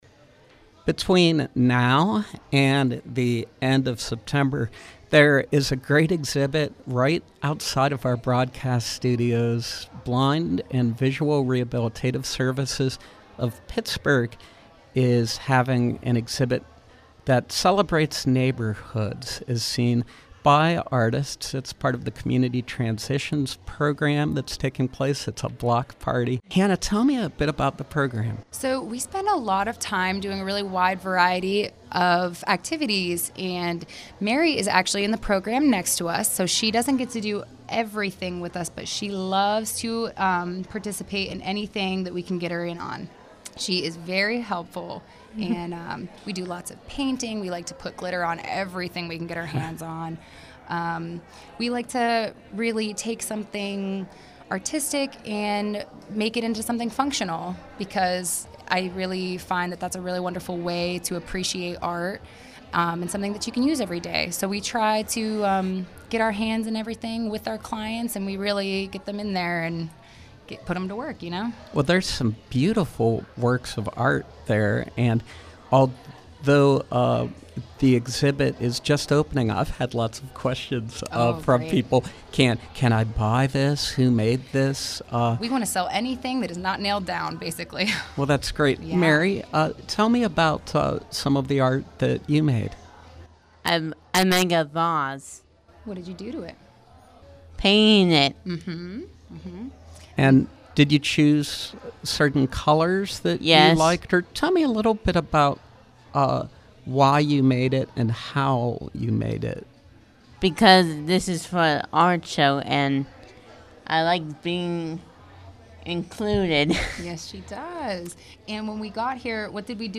From 8/13: Opening night at Blind and Visual Rehabilitation Services Art Show, running through September just outside of the SLB studios at the Children’s Museum of Pittsburgh.